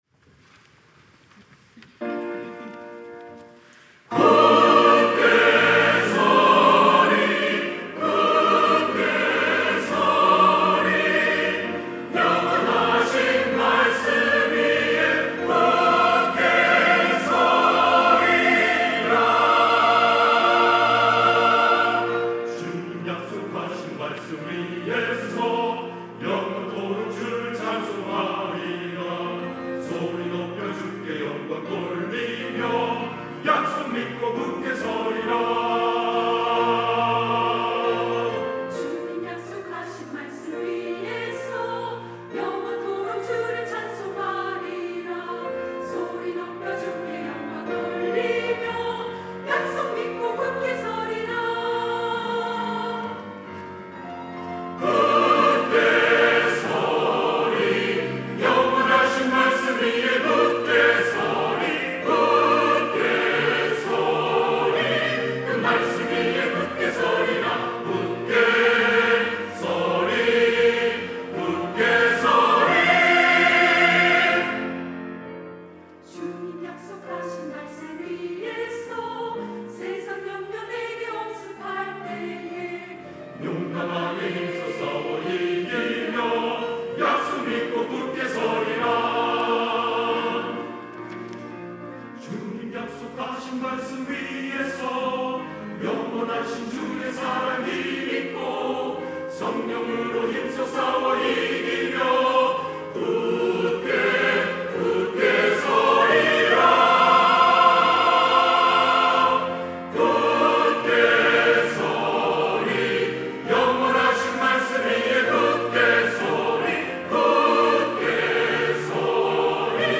할렐루야(주일2부) - 주님 약속하신 말씀 위에서
찬양대